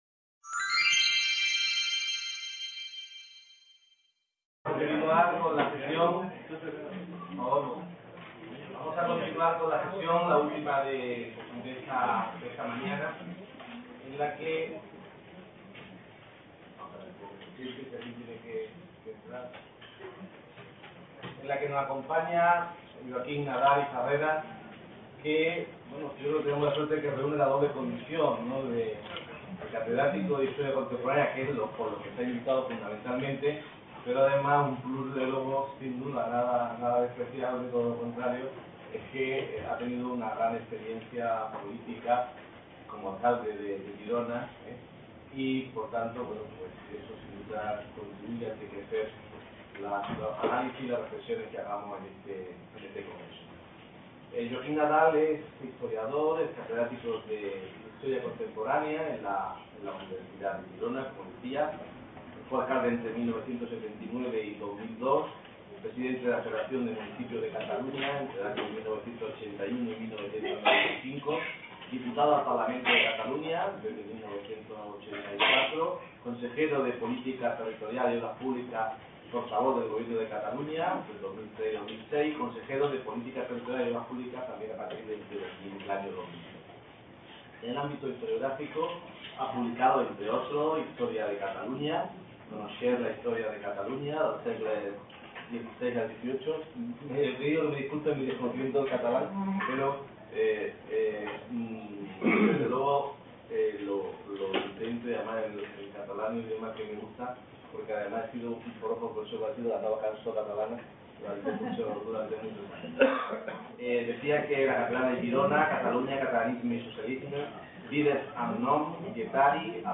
C.A. Almeria - VI Congreso Internacional Historia de la Transición en España, Las instituciones.